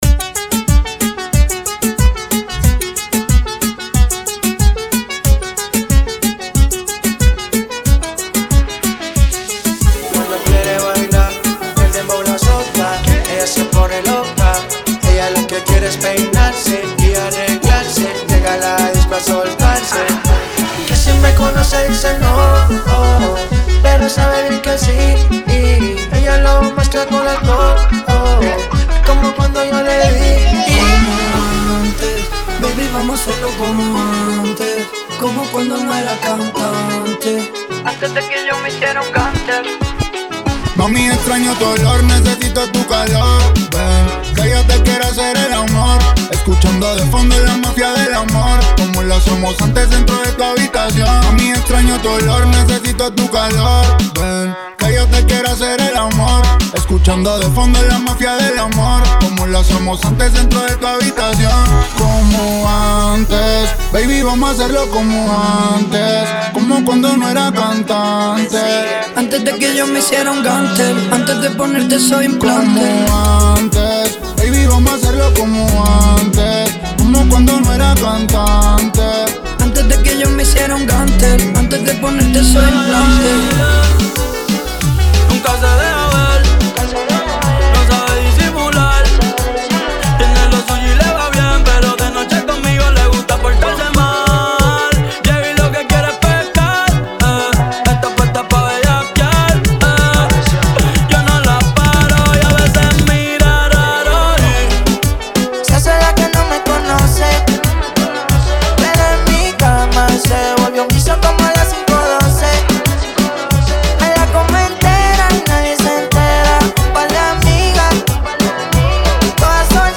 92 bpm